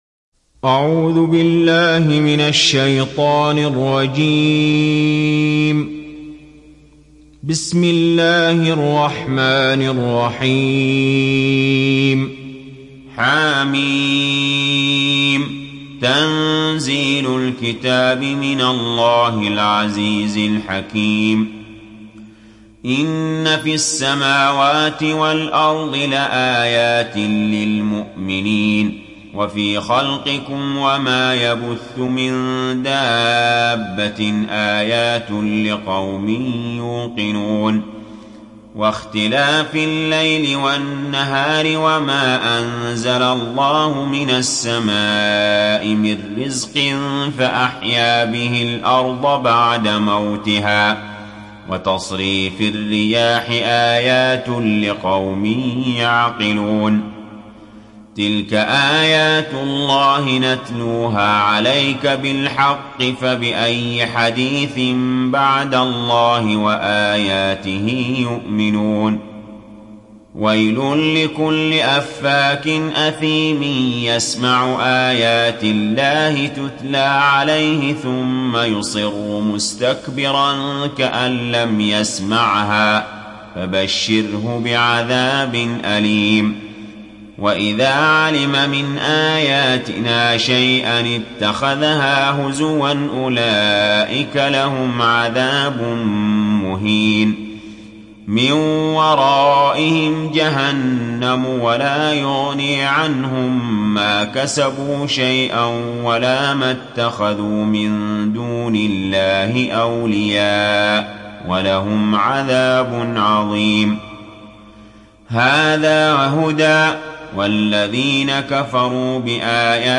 تحميل سورة الجاثية mp3 بصوت علي جابر برواية حفص عن عاصم, تحميل استماع القرآن الكريم على الجوال mp3 كاملا بروابط مباشرة وسريعة